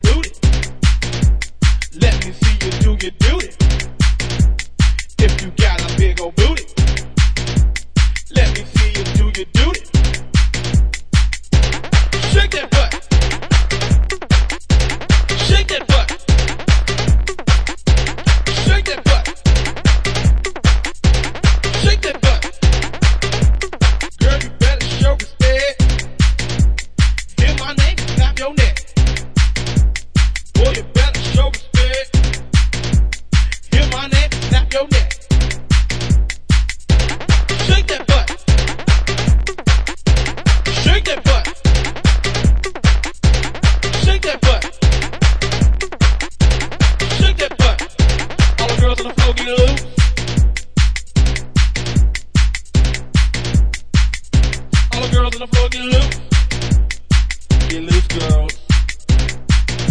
Pumping ghetto tech tracks